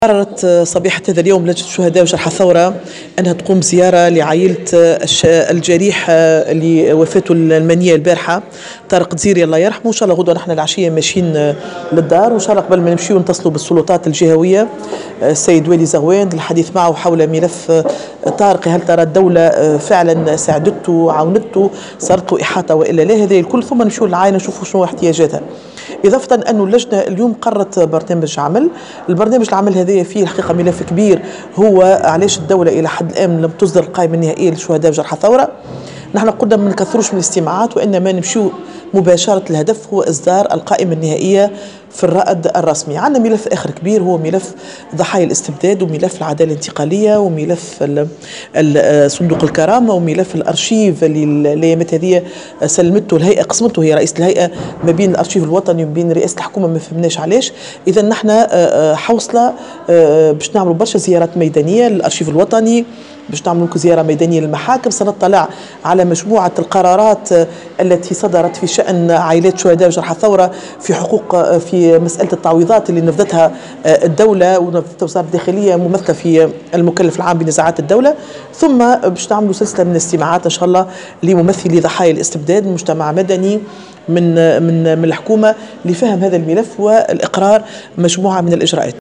قالت رئيسة لجنة شهداء الثورة وجرحاها بالبرلمان يمينة الزغلامي في تصريح...